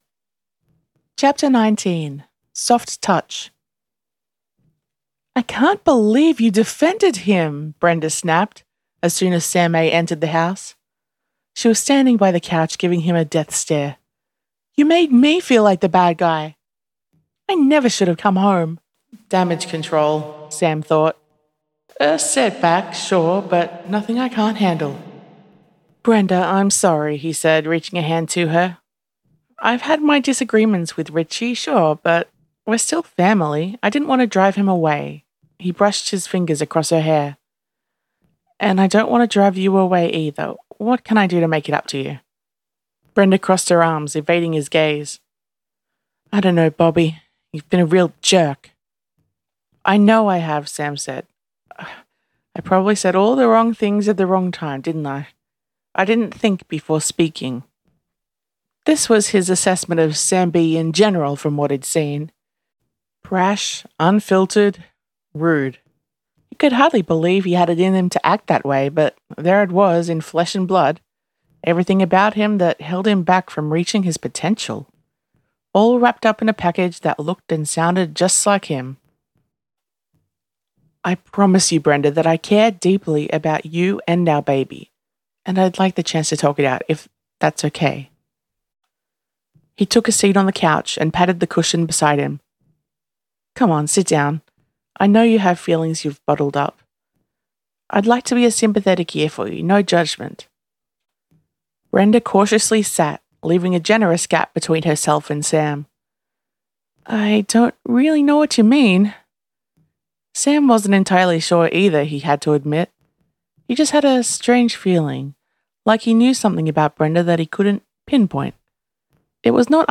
Listen to/download this chapter narrated by the author: